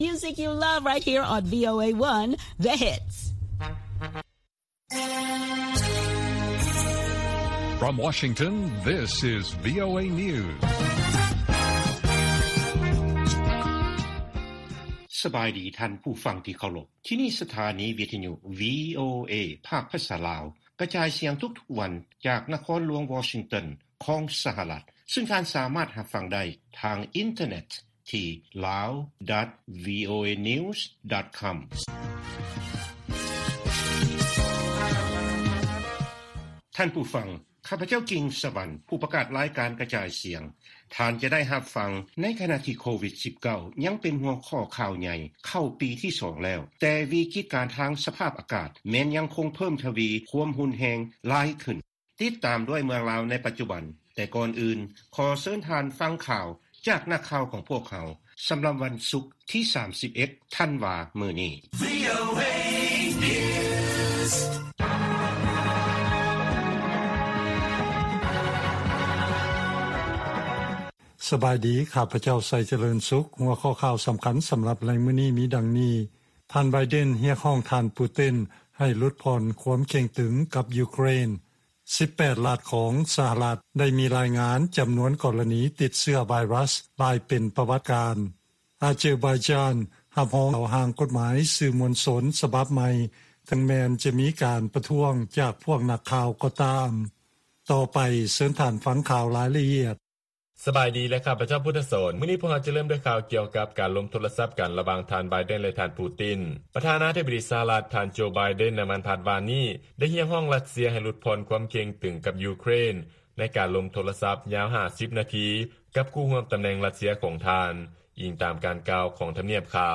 ລາຍການກະຈາຍສຽງຂອງວີໂອເອ ລາວ: ສິບແປດລັດຂອງສະຫະລັດ ໄດ້ມີການລາຍງານຈໍານວນກໍລະນີຜູ້ຕິດເຊື້ອໄວຣັສ ເປັນປະຫວັດການ